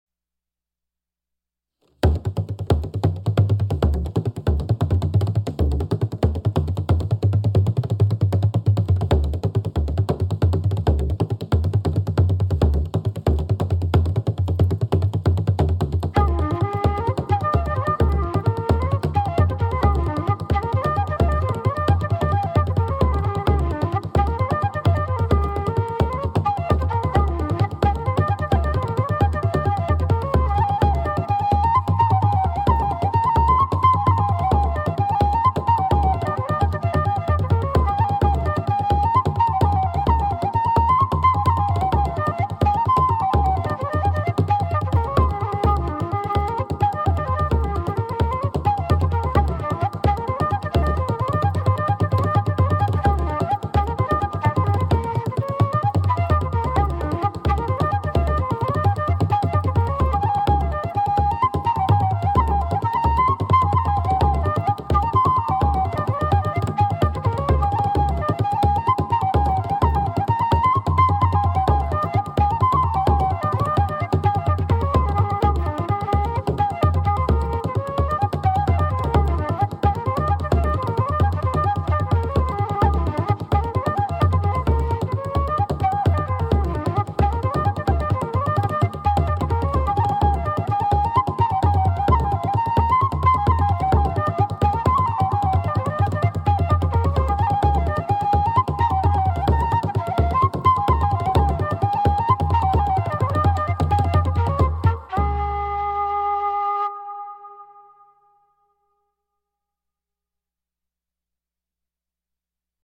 音乐风格：New Age / Native American / World